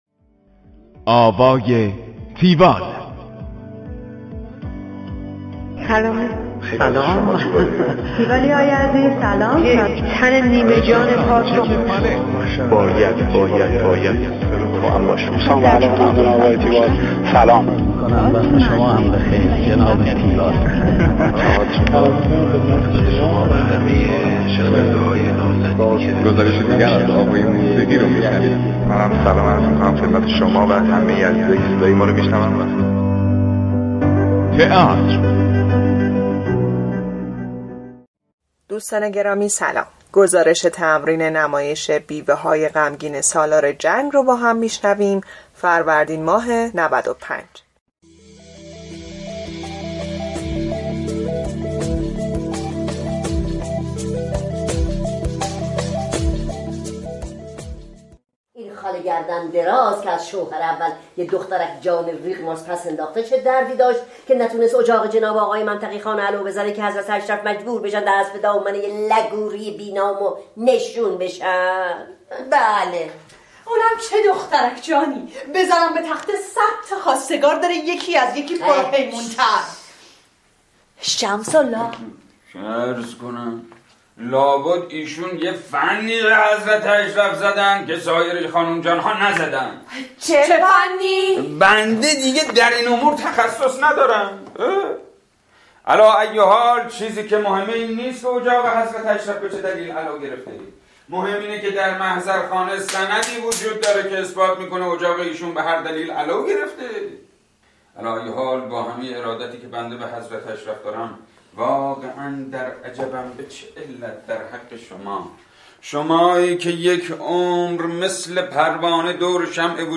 گزارش آوای تیوال از نمایش بیوه های غمگین سالار جنگ